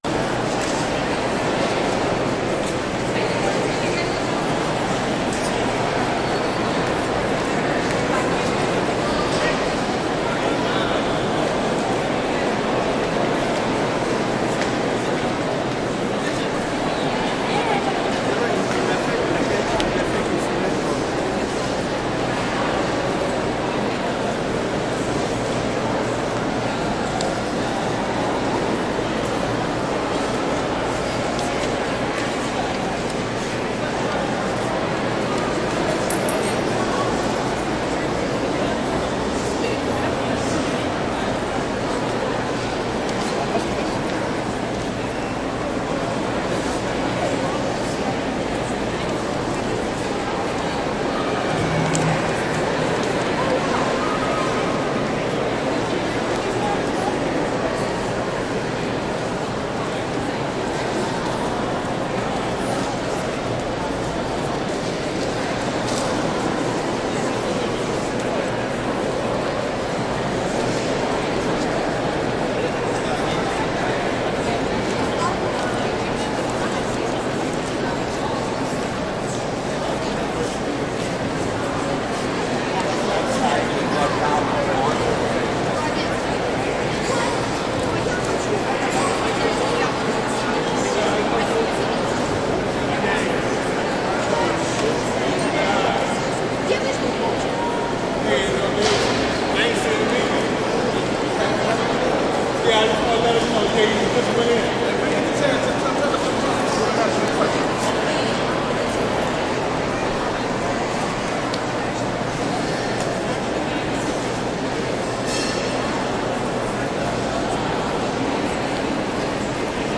纽约市 " 人群在大型宽幅水洗中忙于移动的大型大厅或博物馆MET入口2 美国纽约市
描述：人群int大洗宽忙着移动大厅或博物馆MET入口2纽约，USA.flac
Tag: 大厅 NYC 移动 洗净 或者 博物馆 INT MET 人群 美国 入口